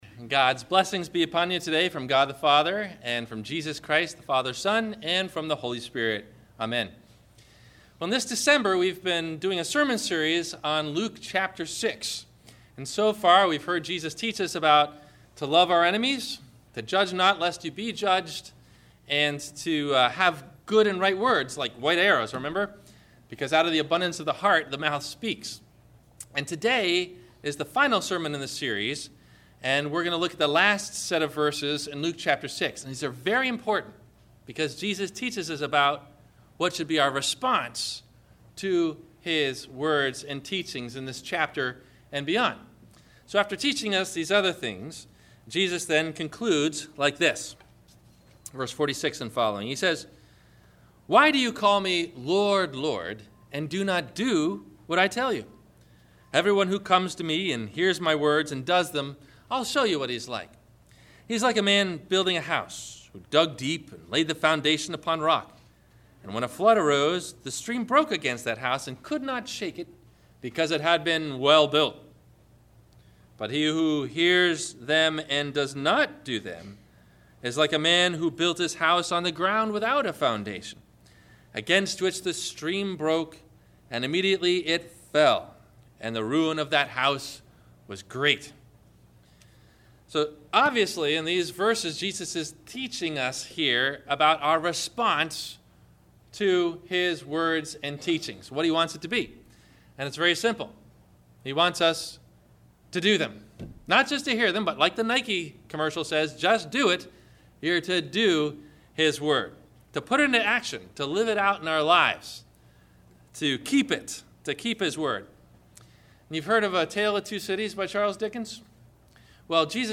Two Lives, Two Houses, Two Destinies – Sermon – December 30 2012